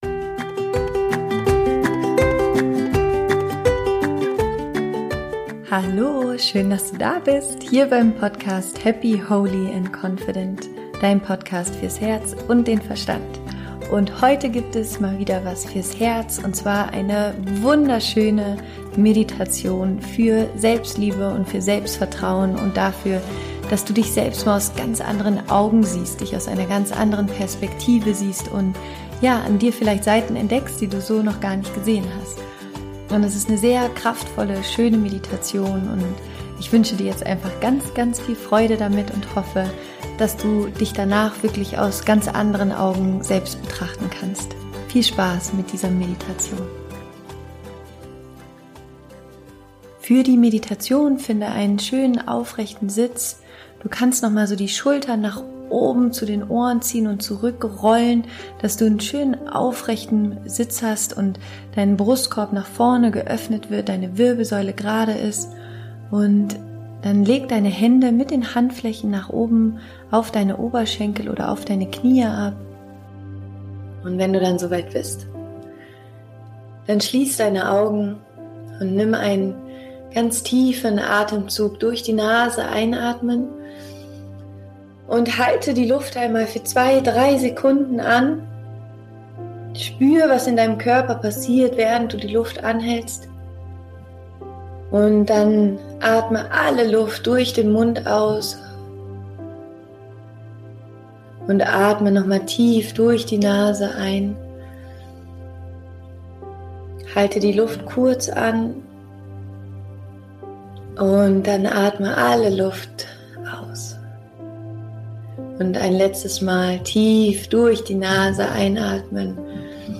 Meditation: Tiefe Selbstliebe entwickeln
Finde für die Meditation einen aufrechten und bequemen Sitz, schließe deine Augen und lass dich von mir durch die Meditation führen.